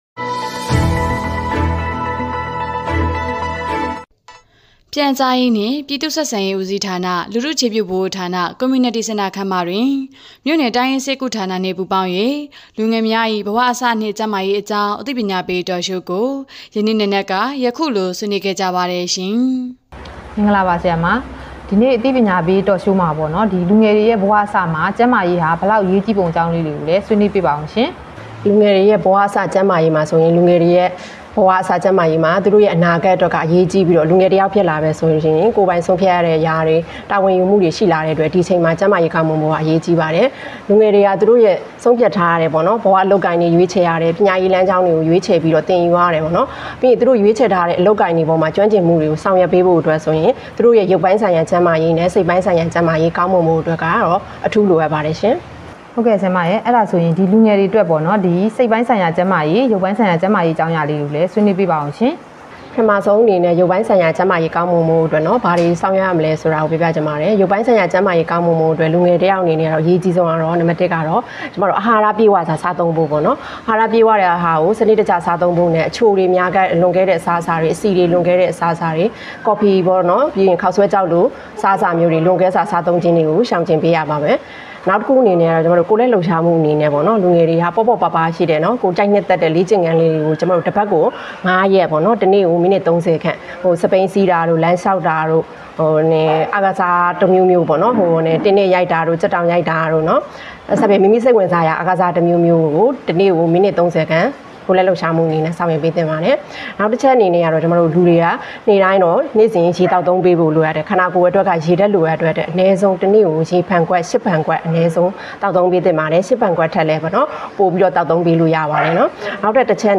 ခရမ်းမြို့နယ်တွင် လူငယ်များ၏ ဘဝအစနှင့်ကျန်းမာရေးအသိပညာပေး Talk Show ပြု...